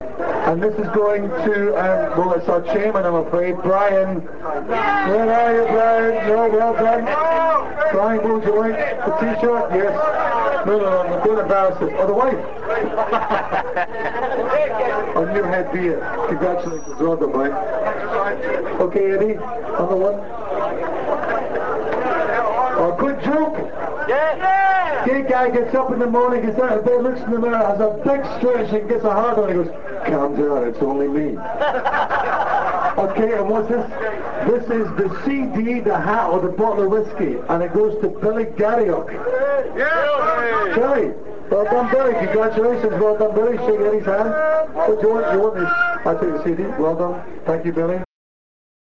London Hibs Annual Burns Night Supper was held on Saturday 22nd January 2000 at the Kavanagh's Pub, Old Brompton Road.